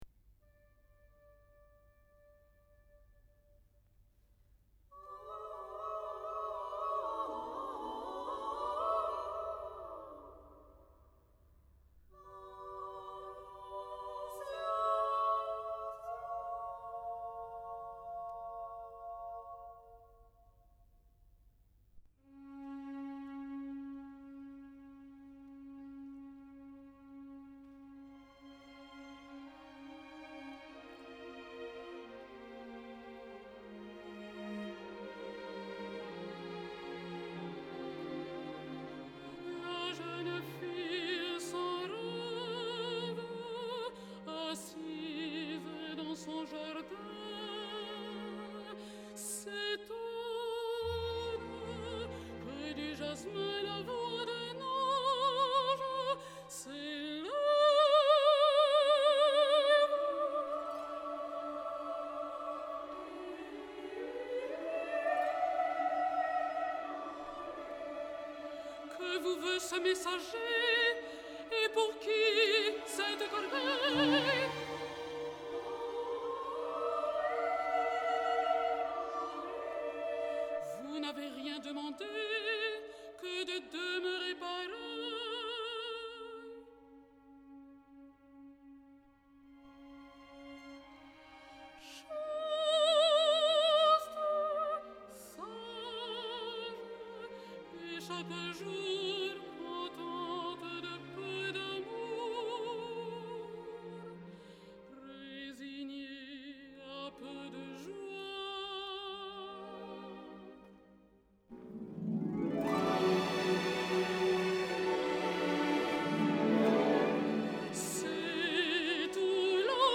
Caplet’s late work Le Miroir de Jésus, mystères du Rosaire (1924) also uses wordless vocalization, the musical style still containing traces of Debussy’s influence.
The larger women’s chorus acts as accompaniment to the female soloist, designated in the score as “voix principale.” At times, prolonged singing à bouche fermée or on vowel sounds, and the melismatic, chant-like nature of the choral passages evoke the spirit of dramatic vocalization.